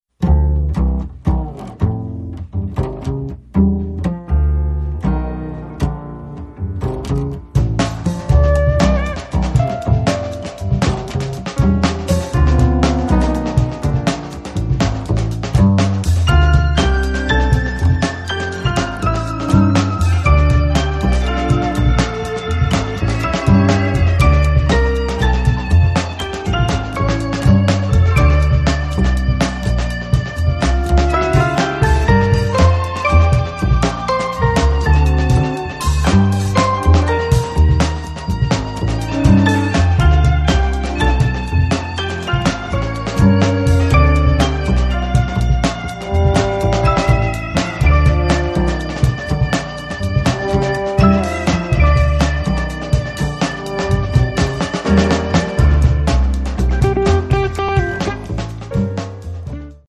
keyboards
guitar
drums
bass